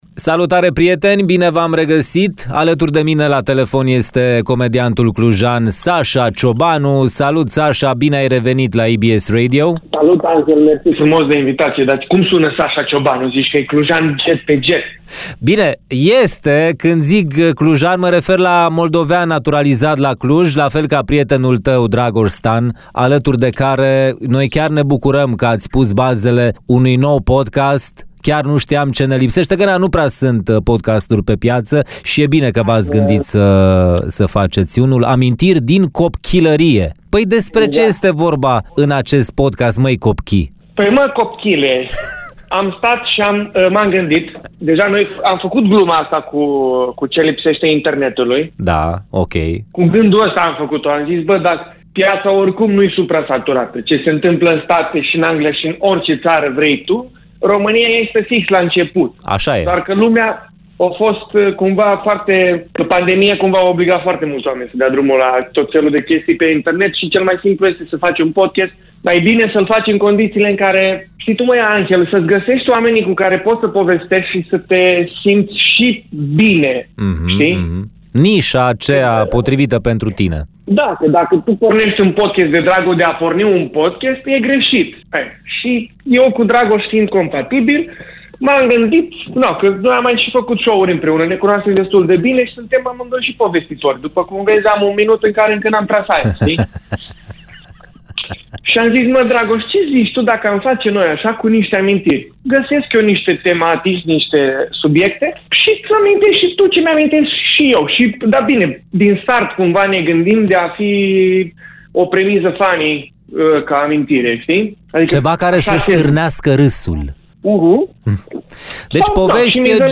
Într-un interviu acordat EBS Radio